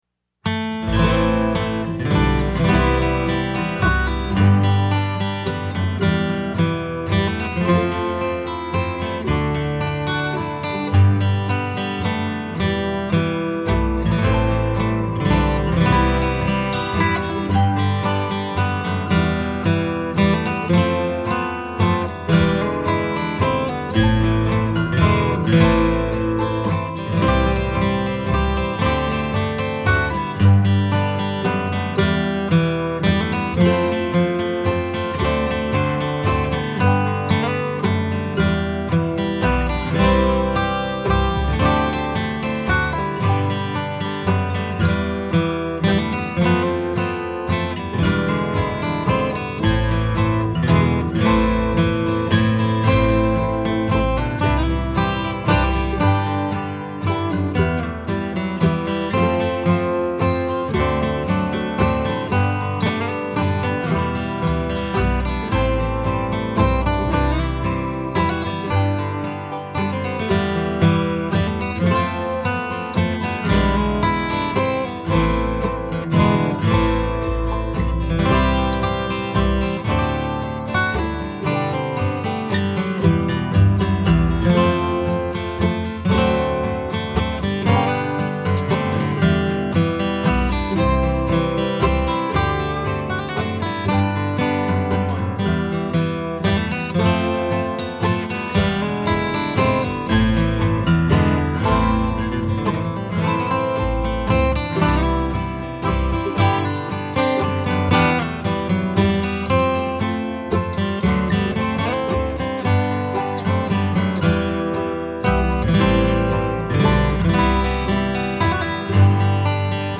DIGITAL SHEET MUSIC - GUITAR SOLO
• Christmas, Bluegrass, Flatpicking, Guitar Solo